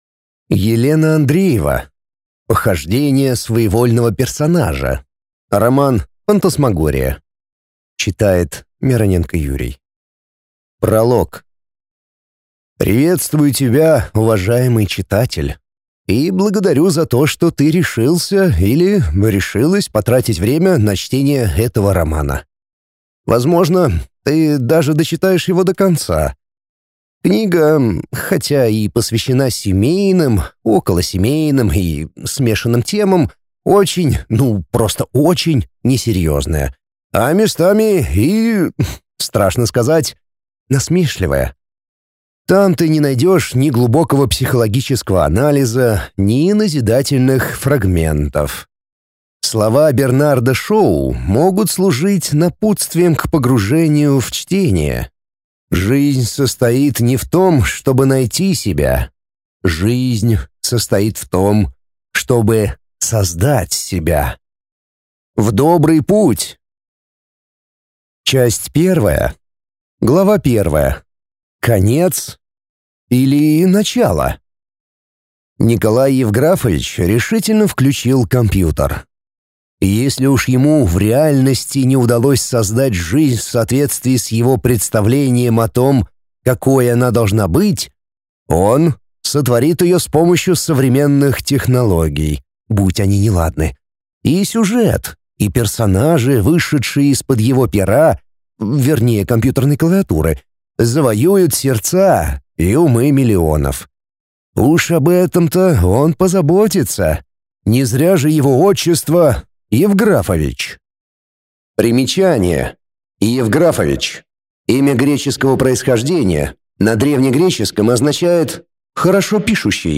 Аудиокнига Похождения своевольного персонажа. Роман-фантасмагория | Библиотека аудиокниг